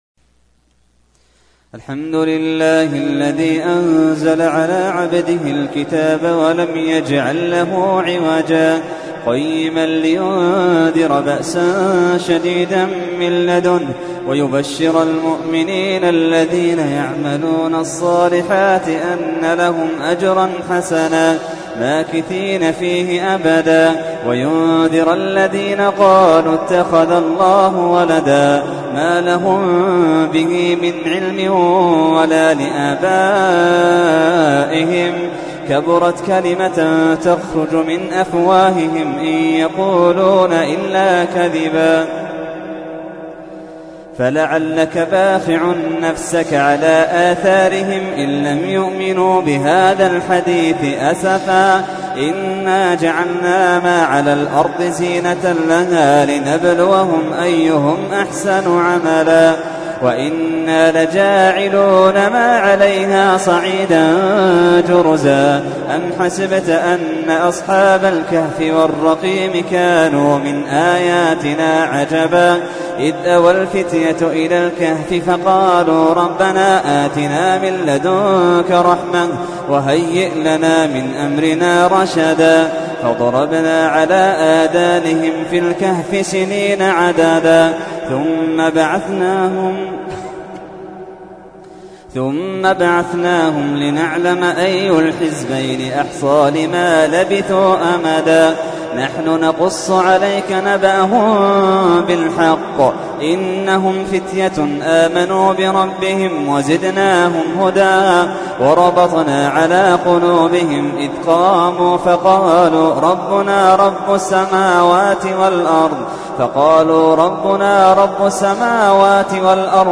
تحميل : 18. سورة الكهف / القارئ محمد اللحيدان / القرآن الكريم / موقع يا حسين